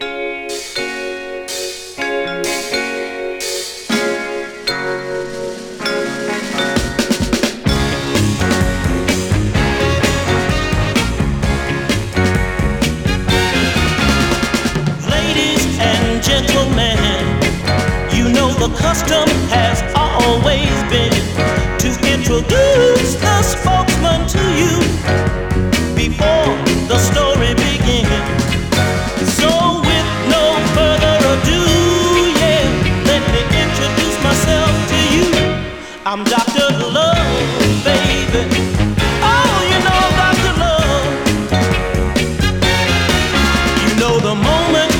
Soul, Funk　UK　12inchレコード　33rpm　Mono